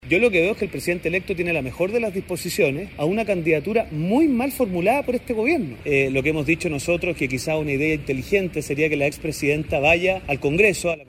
Tras la reunión sostenida entre el presidente electo José Antonio Kast y Bachelet, el diputado RN e integrante de la Comisión de Relaciones Exteriores, Diego Schalper, afirmó que ese encuentro refleja una disposición al diálogo que —a su juicio— no estuvo presente en la forma en que el actual gobierno impulsó la candidatura.